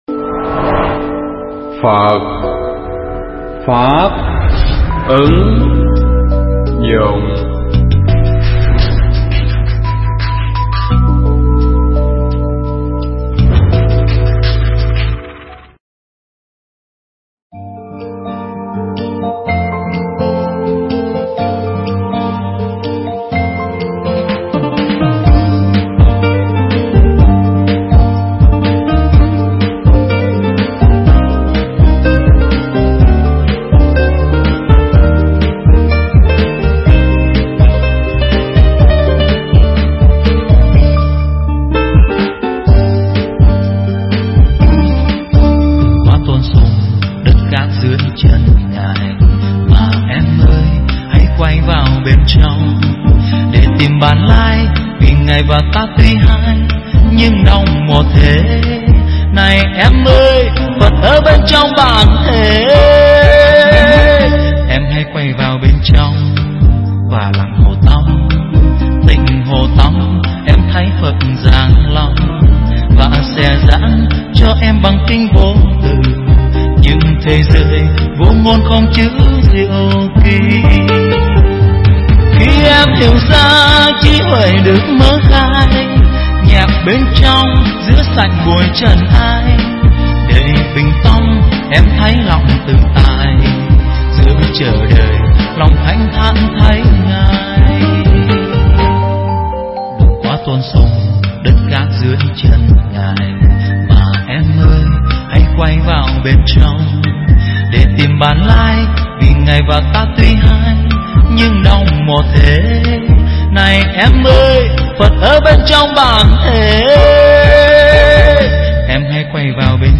pháp thoại Đạo Phật Duy Tâm Hay Duy Vật
thuyết giảng tại chùa Ấn Quang (quận 10, TPHCM)